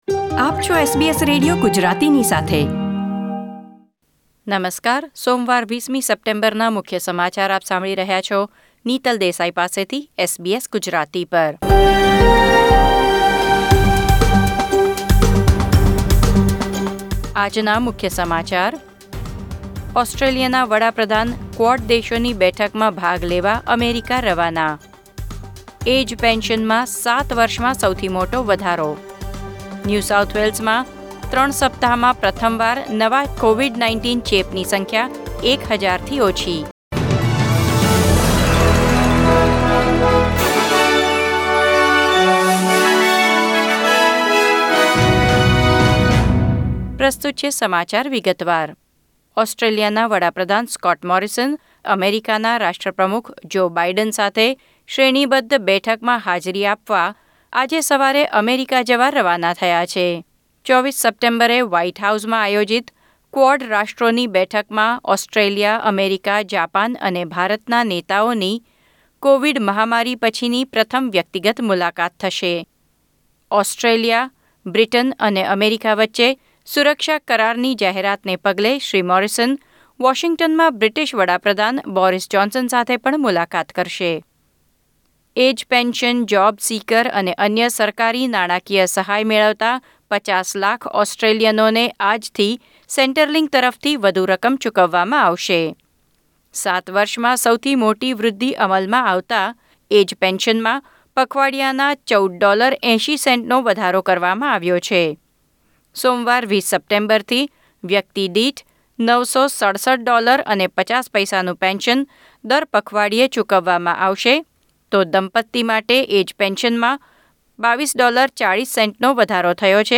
SBS Gujarati News Bulletin 20 September 2021